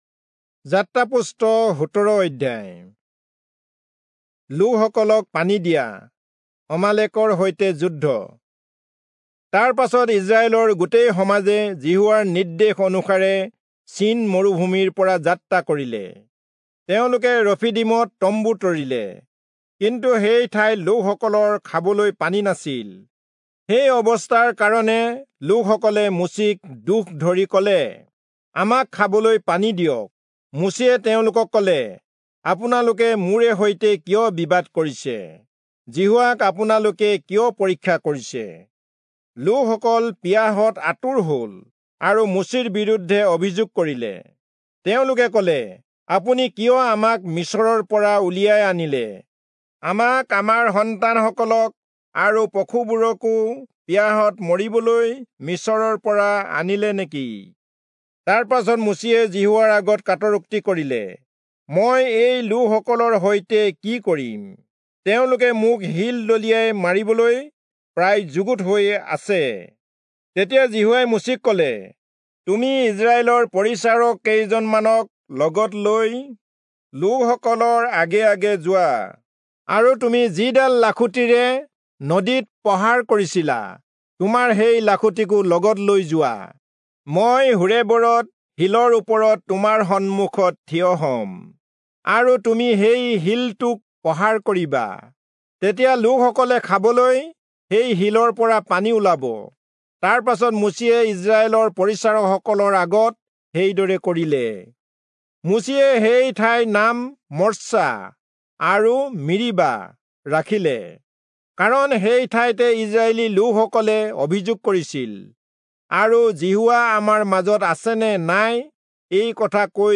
Assamese Audio Bible - Exodus 30 in Ervbn bible version